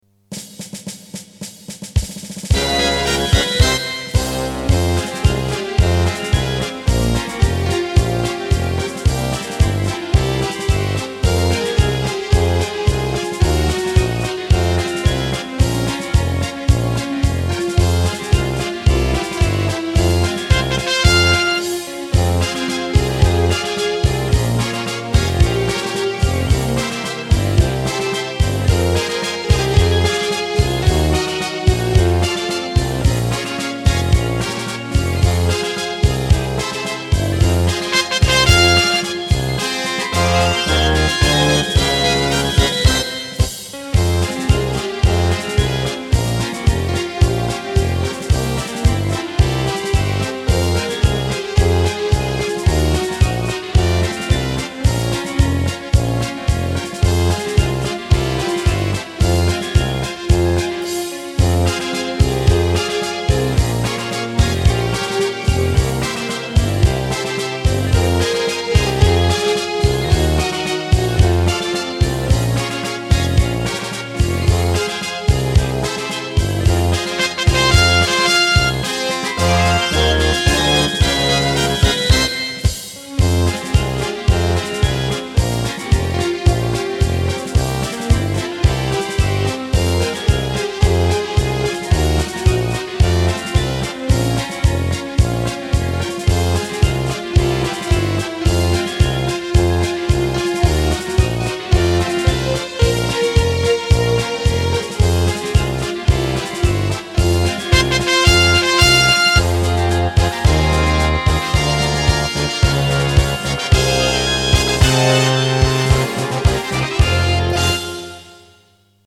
42517_- Moy dedushka geroy minus.mp3